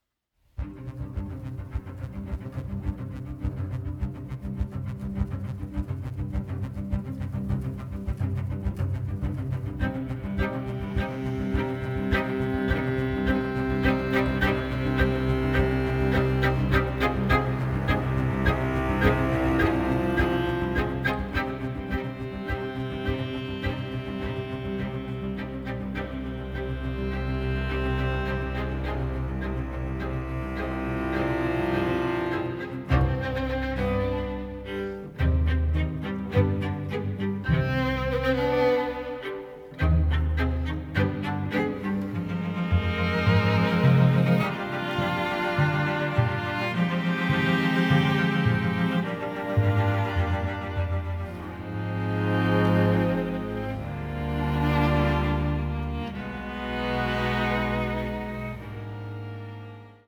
intense, emotional and moving large-orchestral scores
The music was recorded at Air Studios, London